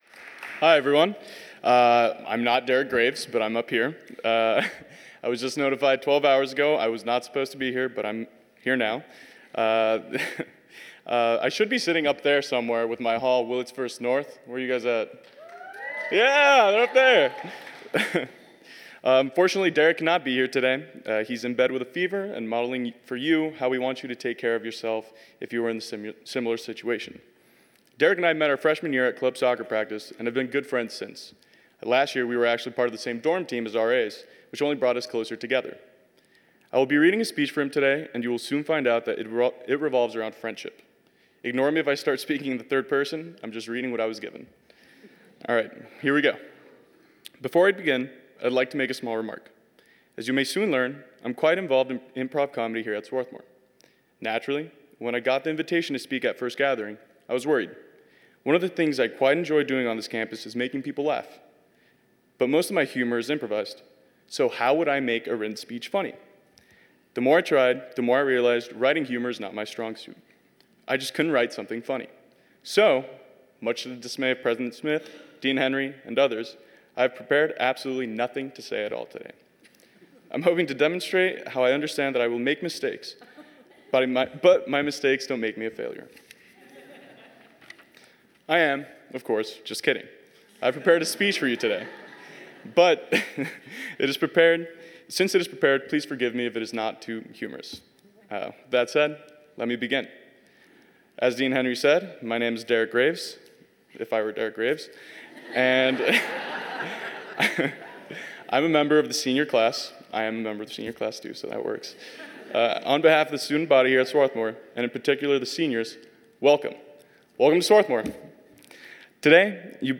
First Gathering 2017: President Valerie Smith